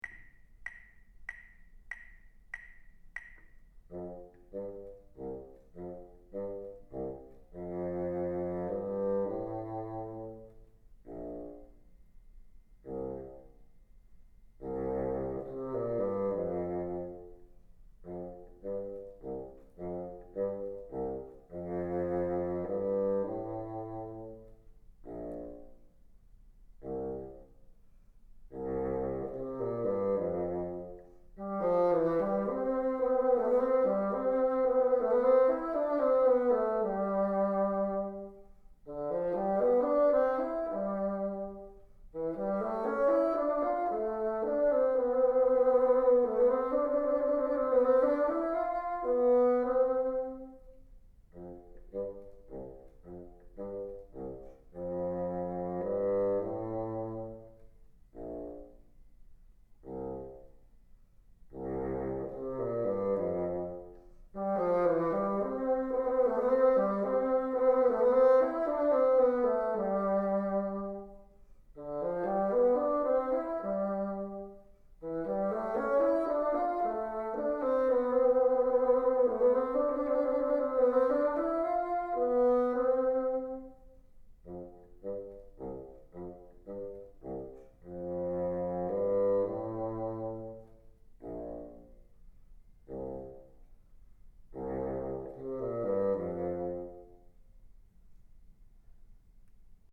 Learn to play it by ear in the key of C Major, starting on C2, then play it as a round with the sound clip.
Parts Together Duet Teacher Part